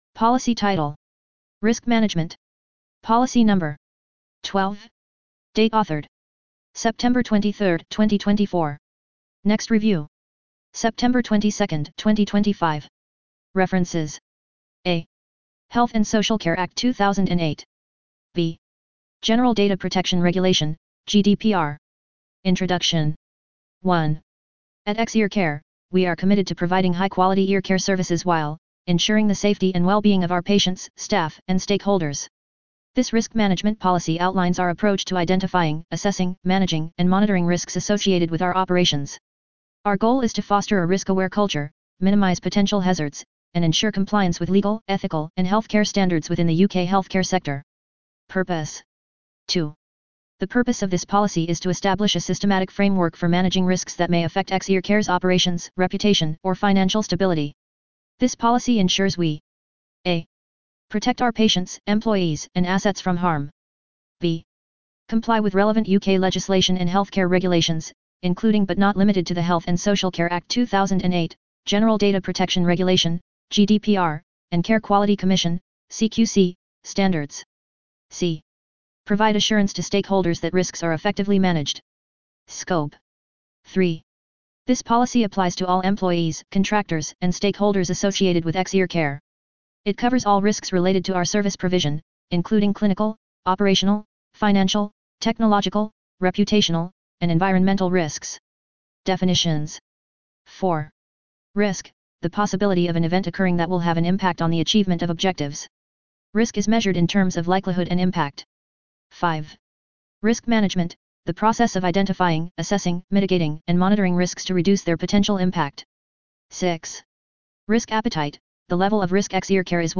Narration of Risk Management Policy